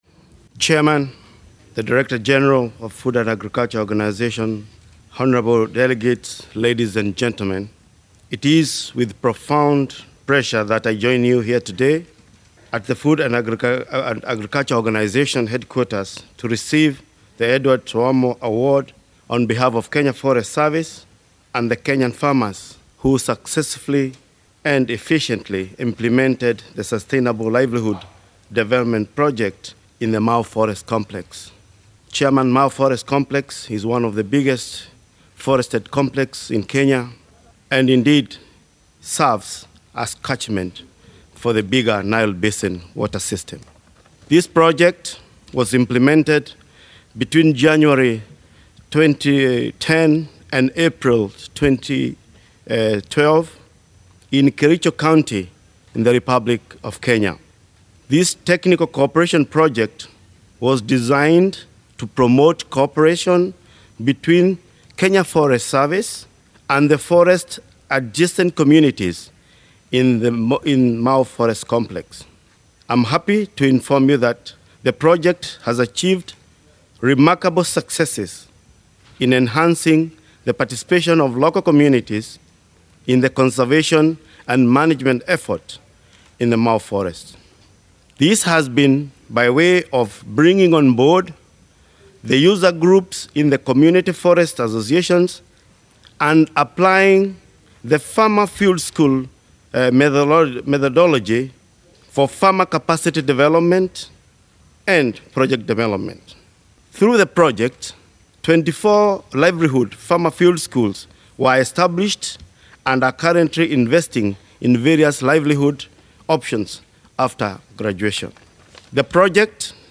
15 June 2013, Rome-- The 38th FAO Conference Awards ceremony honours the outstanding contributions of individuals and institutions to global food security.
by Mr David K. Mbugua, Director of Kenya Forest Service for the Edouard Saouma Award.